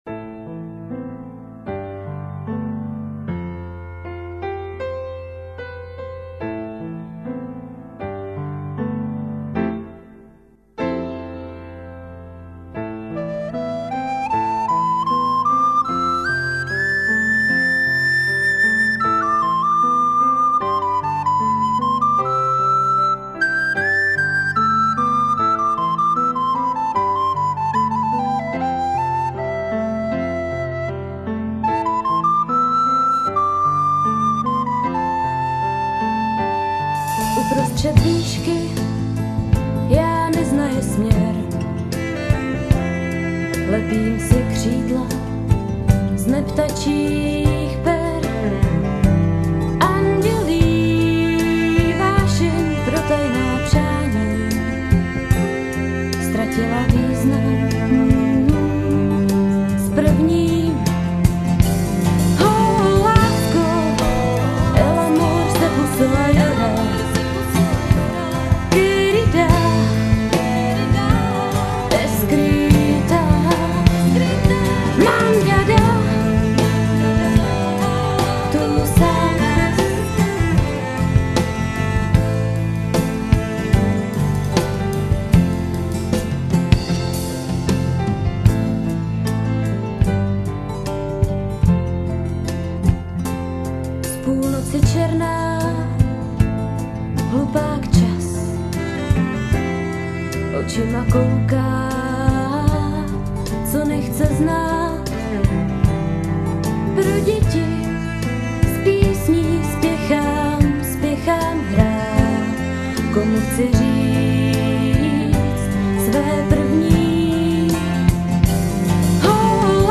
kategorie ostatní/písně
a ted mi příjde taková smutná....ale krásná...